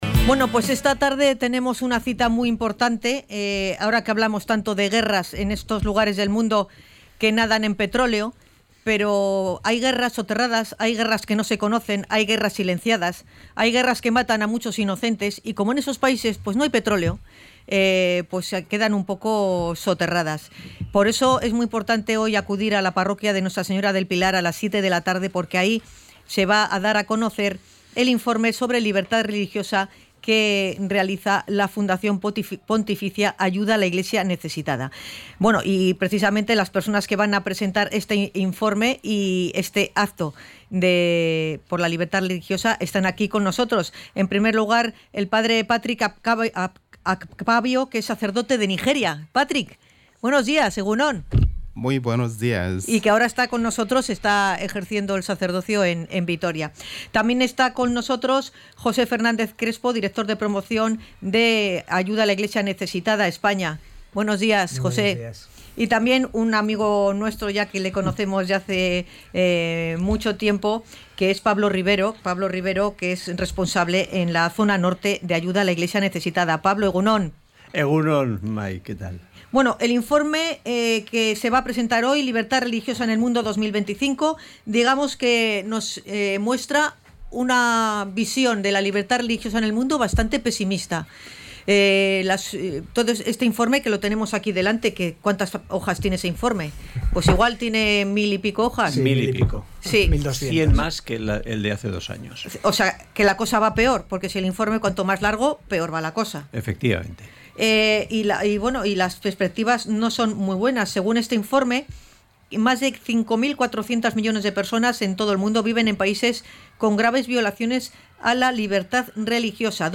Libertad-religiosa-entrevista.mp3